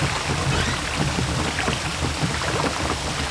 water_mill.wav